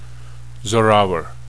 Pronunciation
zorawar.wav